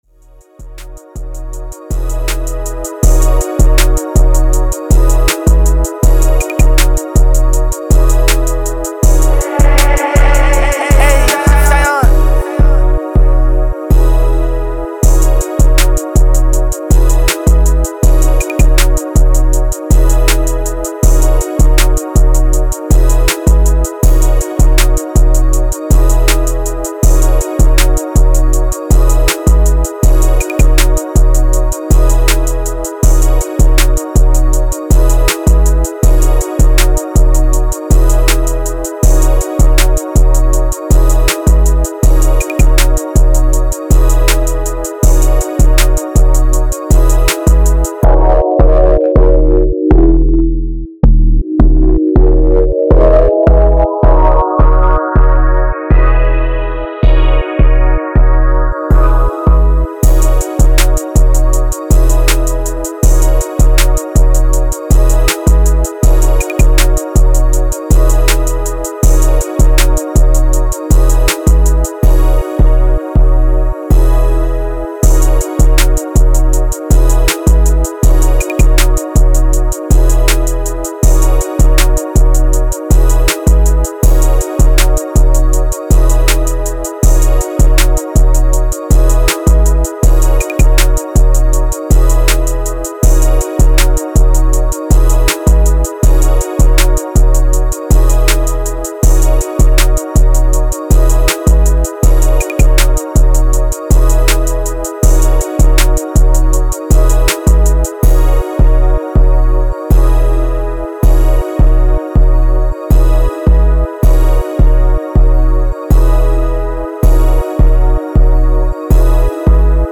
130 G# Minor